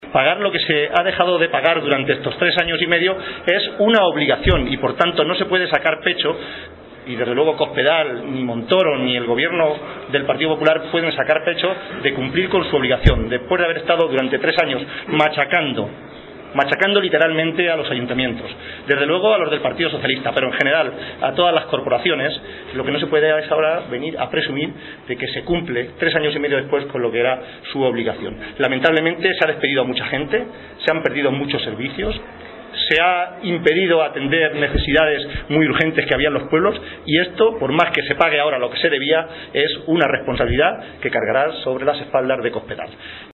En la consecución de ese objetivo y de conectar con los ciudadanos, Luena participó en Ciudad Real en una asamblea abierta junto al secretario general del PSOE en la provincia, José Manuel Caballero, y la candidata a la Alcaldía de la capital, Pilar Zamora.